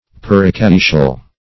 Perichaetial \Per`i*chae"ti*al\